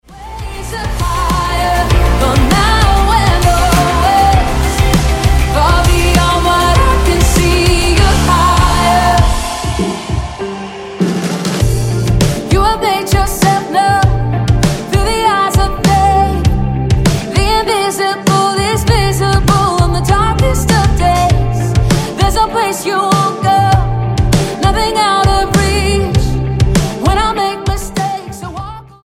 STYLE: Pop
characteristically powerful vocals take centre stage here